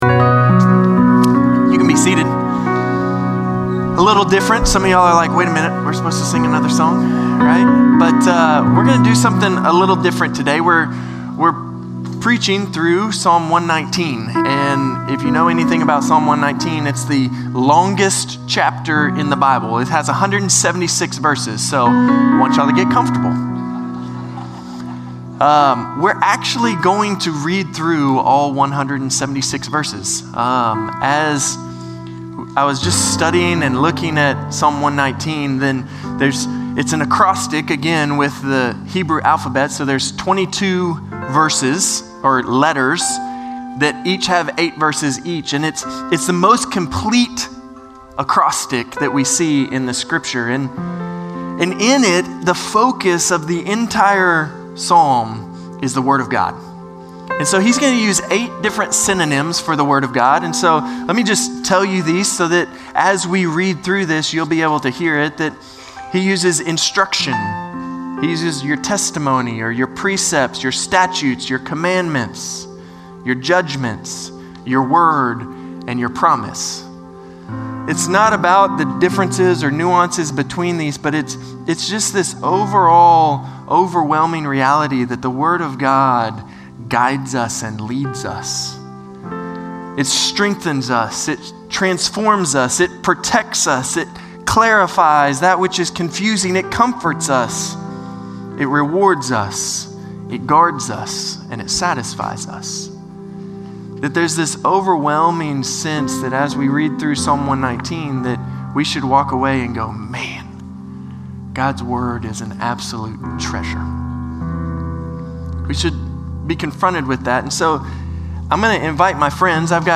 Norris Ferry Sermons Feb. 16, 2025 -- The Book of Psalms -- Psalm 119 Feb 16 2025 | 00:26:22 1x 00:00 / 00:26:22 Subscribe Share Spotify RSS Feed Share Link Embed